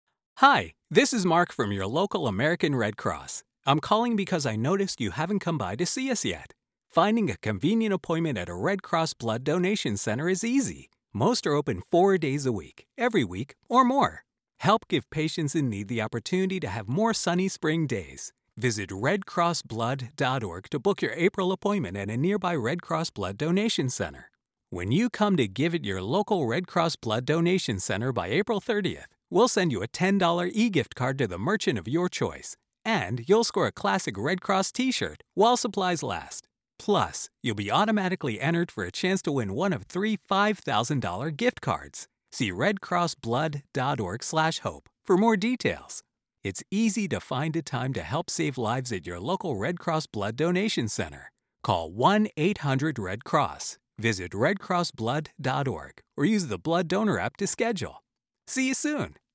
Englisch (Amerikanisch)
Kommerziell, Natürlich, Unverwechselbar, Zugänglich, Vielseitig
Erklärvideo